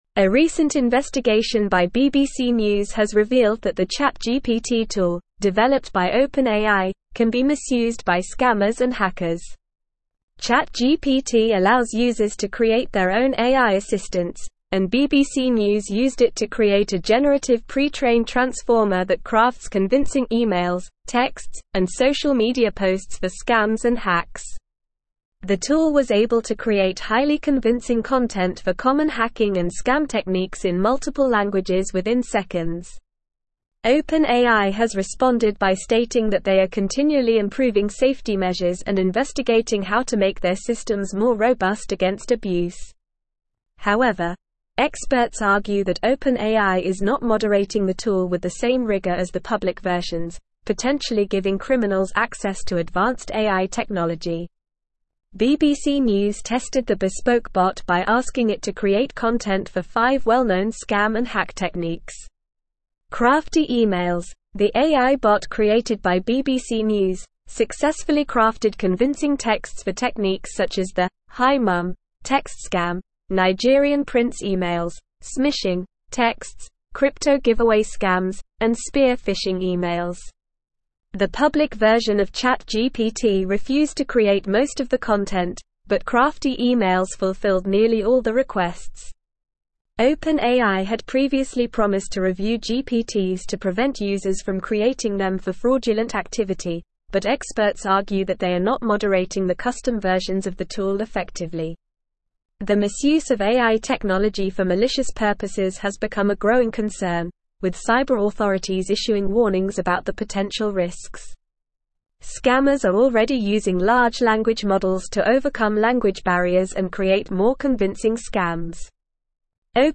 Normal
English-Newsroom-Advanced-NORMAL-Reading-OpenAIs-ChatGPT-Tool-Raises-Concerns-About-Cybercrime.mp3